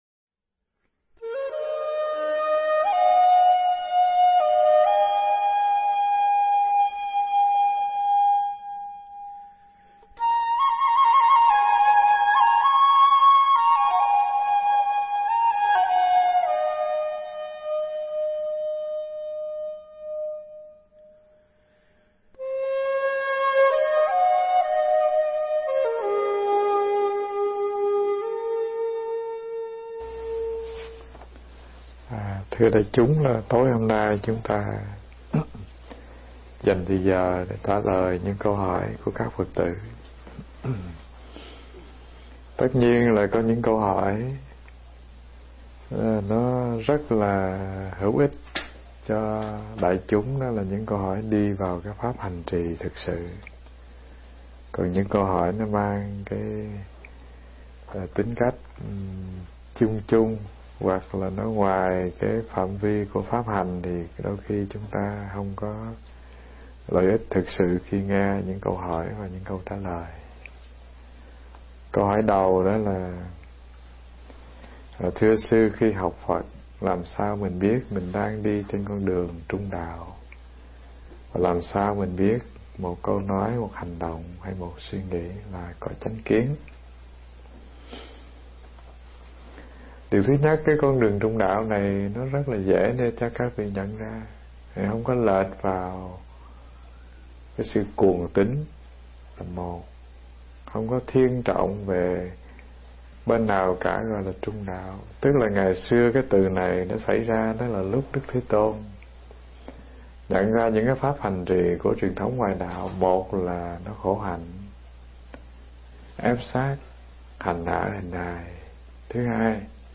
Nghe Mp3 thuyết pháp Nhìn Lại Mình Cho Rõ Phần 4b
Mp3 pháp thoại Nhìn Lại Mình Cho Rõ Phần 4b